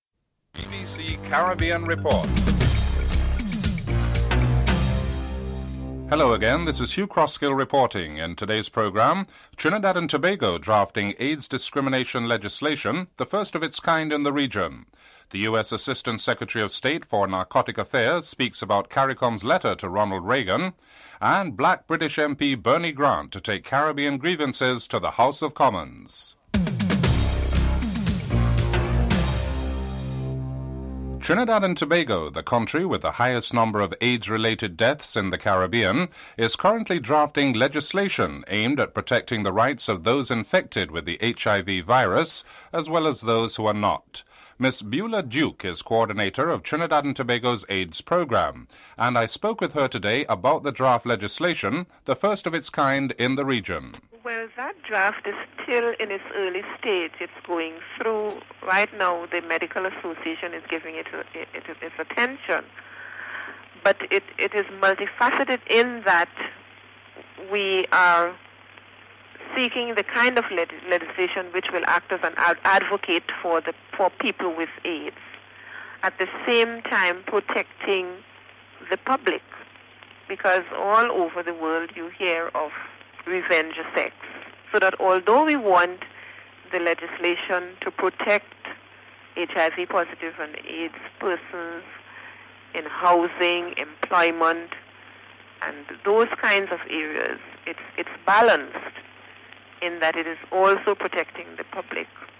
1. Headlines (00:00-00:30)
4. Financial Report.